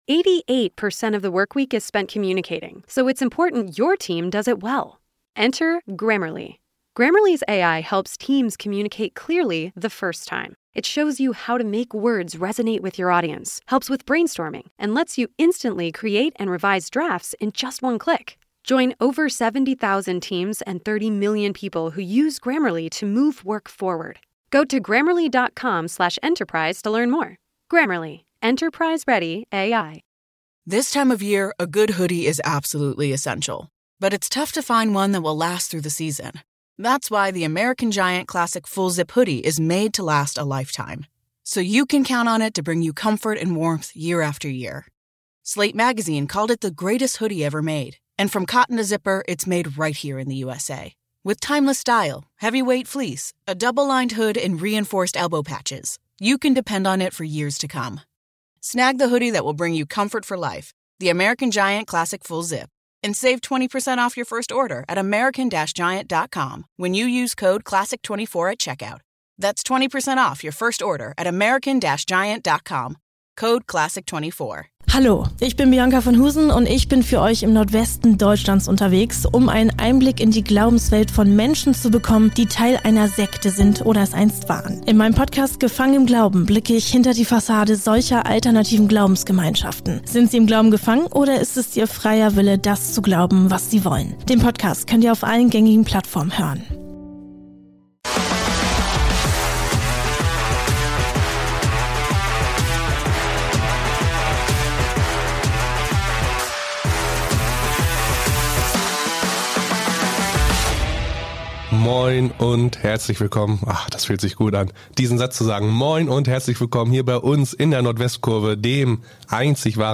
Unter anderem sprechen die beiden über die vielen Neuzugänge – vor allem offensiv können sich Fans in der neuen Saison wohl auf den VfB freuen.